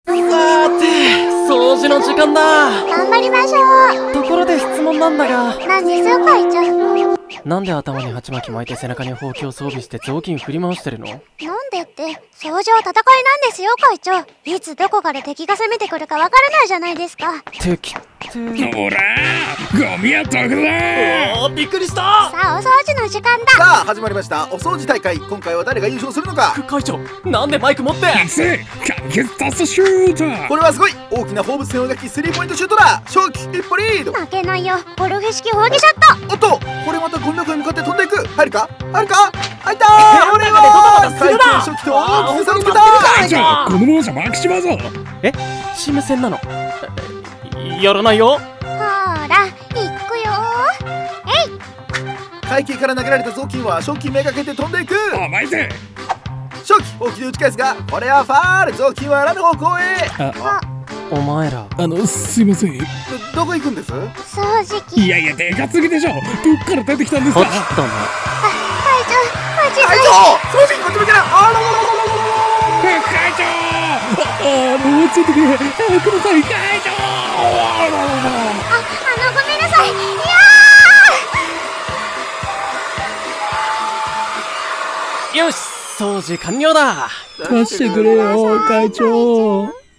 【4人声劇】掃除の時間～生徒会室にて～